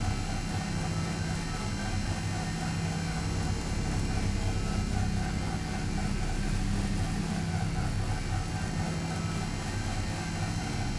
Sfx_tool_hoverpad_build_loop_01.ogg